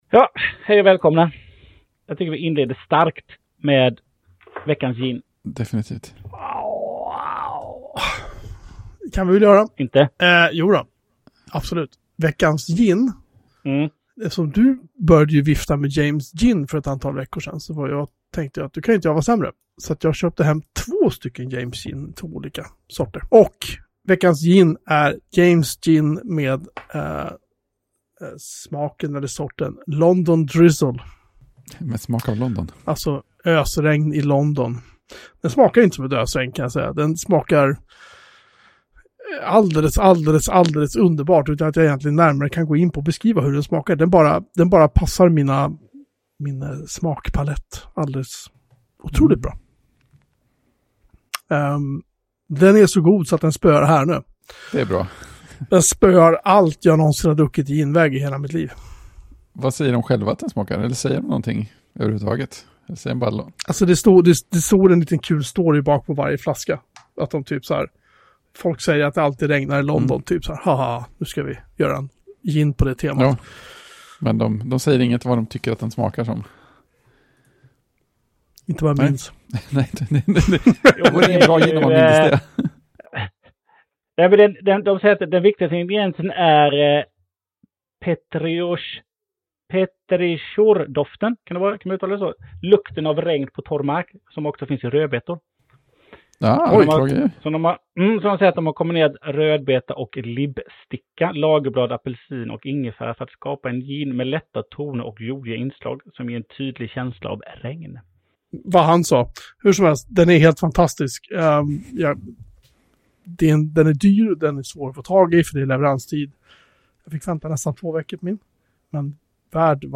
All teknik i världen strulade
pratar genom en potatis